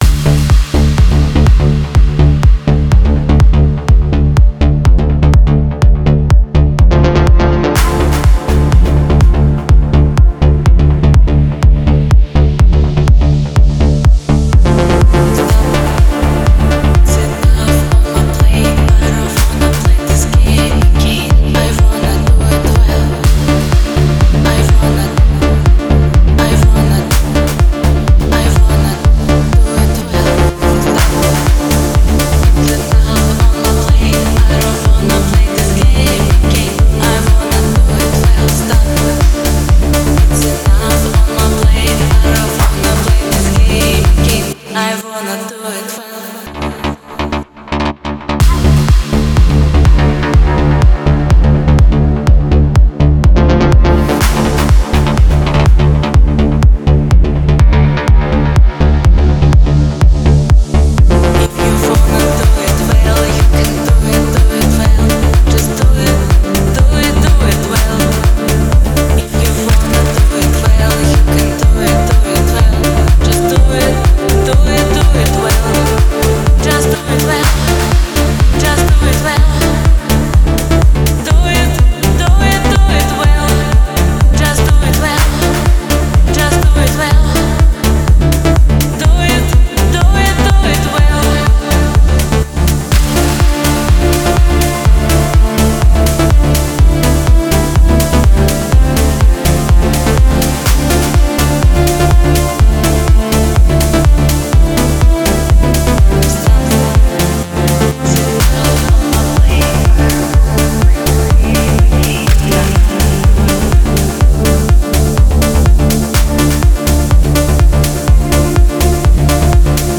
Deep & Tech / Progressive /Afro House / , !